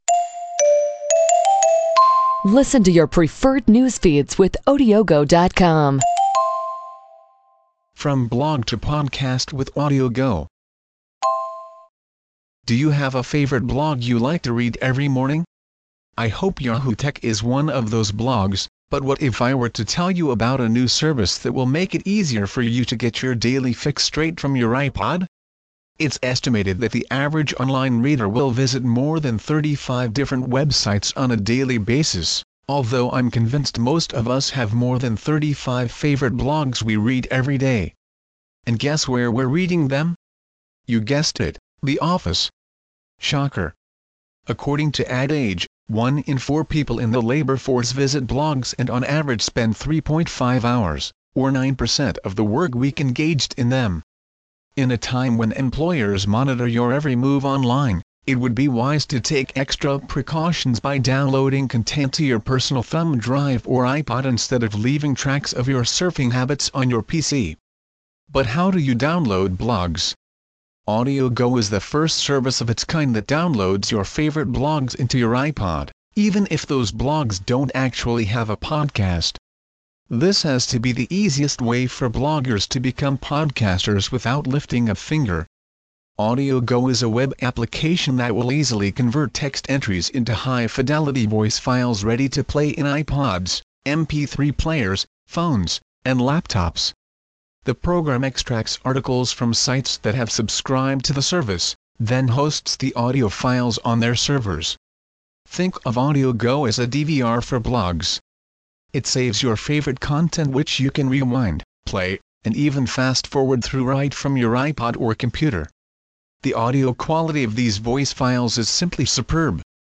Convert RSS feeds into a podcast with text-to-speech (TTS) and Odiogo
It extracts the relevant text from the page (only the white zones in the screenshot below) and converts it into a high quality spoken-word MP3 file that can be listened to on an iPod, MP3 player, phone or the PC.